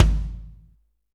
KIK BIG 808L.wav